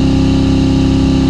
Sounds / Engine / Fast_High.wav
Fast_High.wav